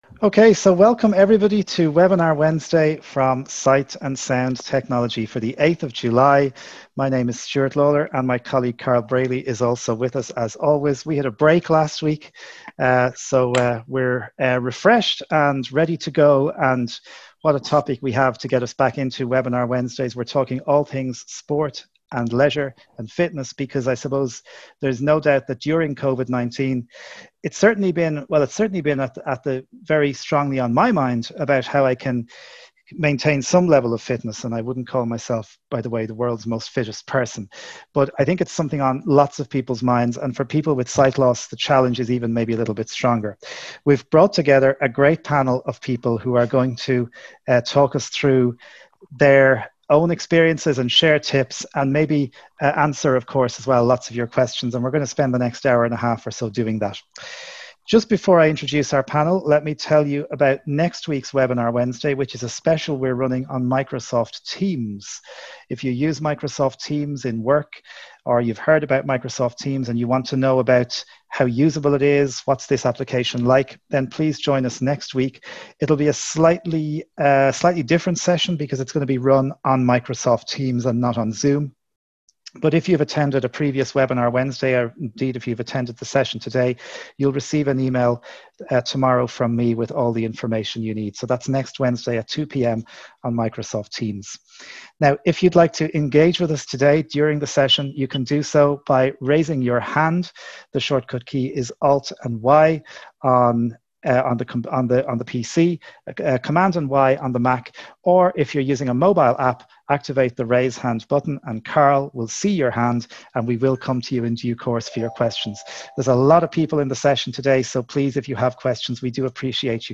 On this week's Webinar Wednesday, we've brought a panel together to talk all things sport, exercise, and leisure activities for people with sight loss. We hear about the hugely successful park run initiatives, which have been sweeping across Ireland and the UK in the last two years.